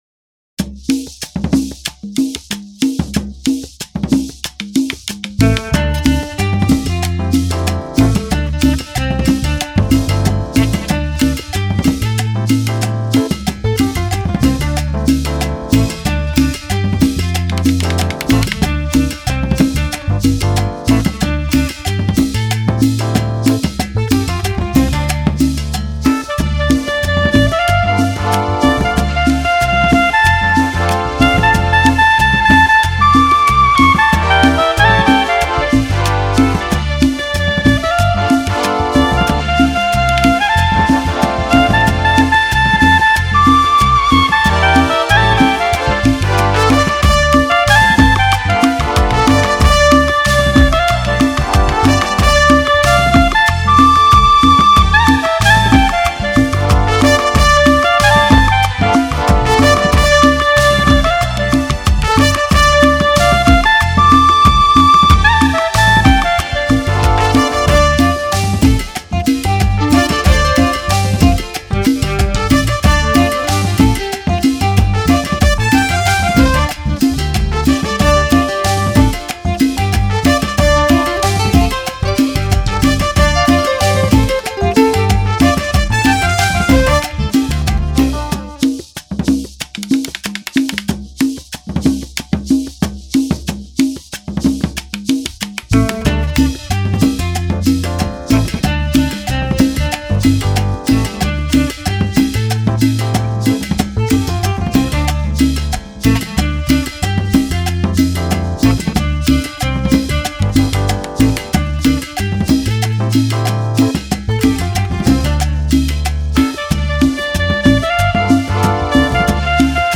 Лучший мировой инструментал